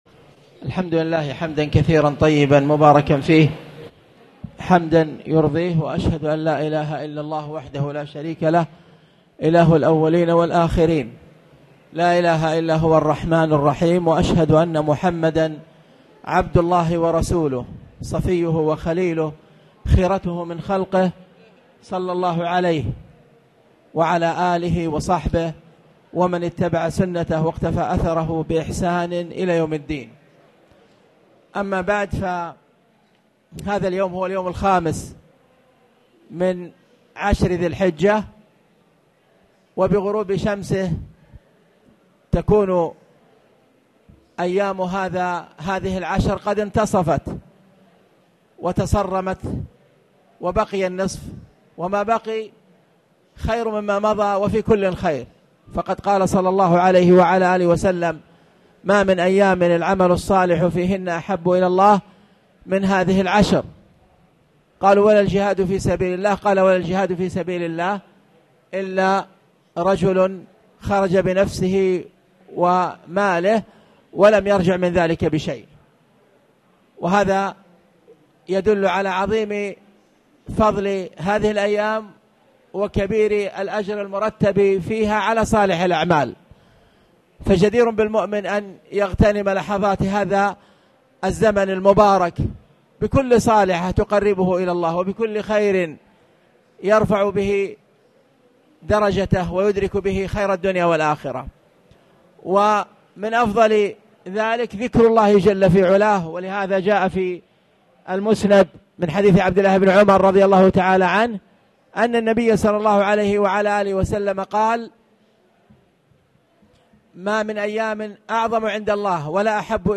تاريخ النشر ٥ ذو الحجة ١٤٣٨ هـ المكان: المسجد الحرام الشيخ